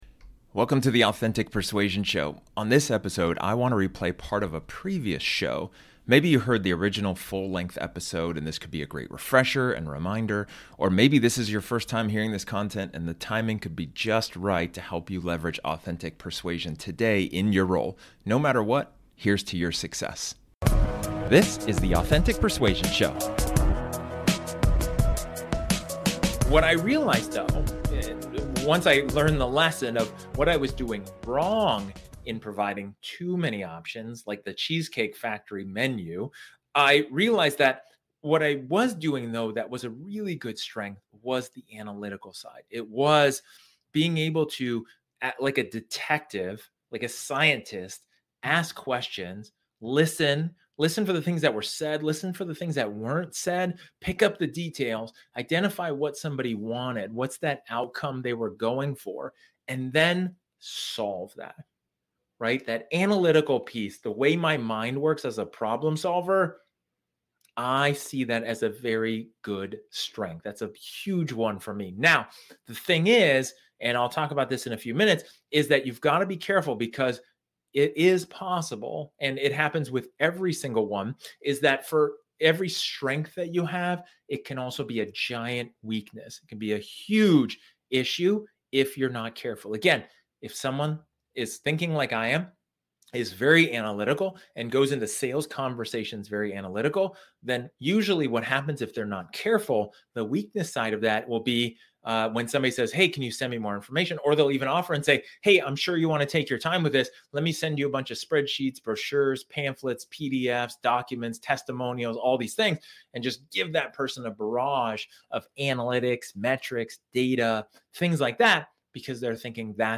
In this episode, This episode is an excerpt from one of my training sessions where I talk about the importance of acknowledging your strengths.